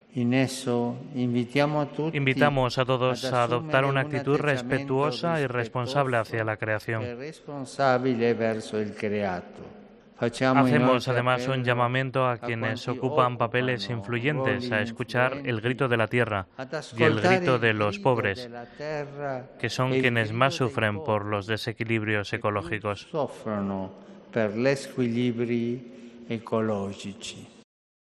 El Papa Francisco hace un llamamiento a mantener una actitud más responsable con el Medio Ambiente. Así se ha pronunciado durante la Audiencia General de los miércoles.